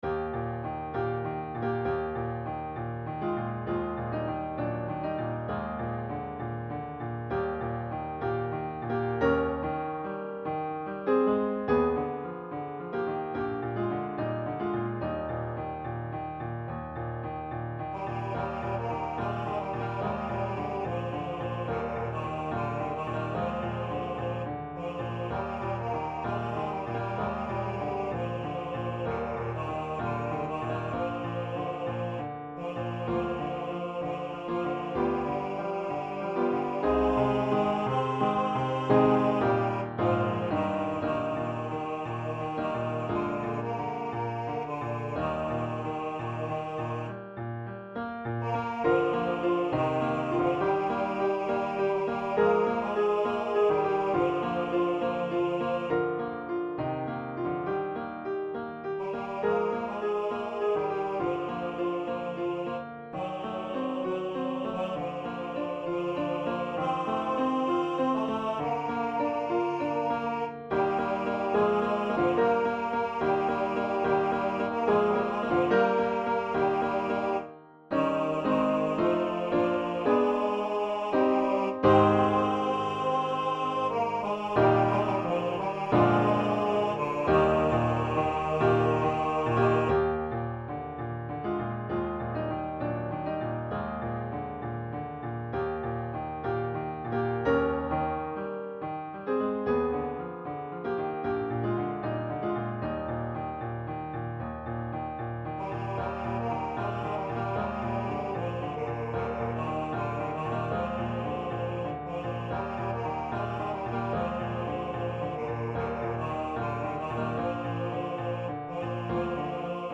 SINGEN: Lieder und Arien für Bass/Bariton